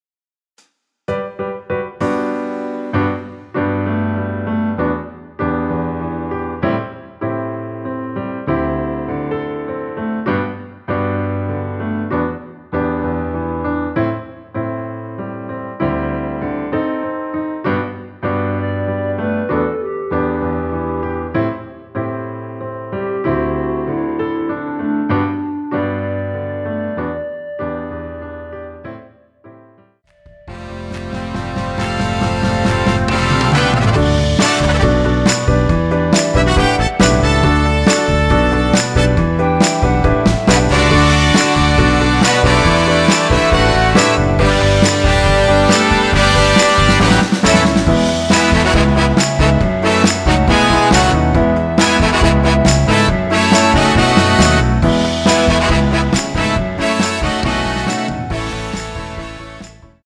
전주 없이 시작 하는 곡이라 인트로 만들어 놓았습니다.
원키 멜로디 포함된 MR입니다.
앞부분30초, 뒷부분30초씩 편집해서 올려 드리고 있습니다.
중간에 음이 끈어지고 다시 나오는 이유는